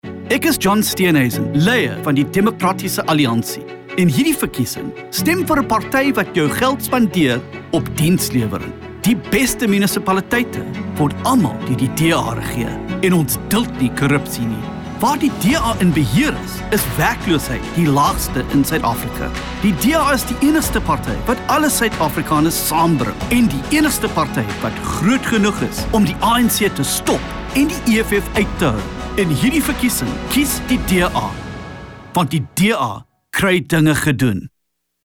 Afrikaans radio advert clips.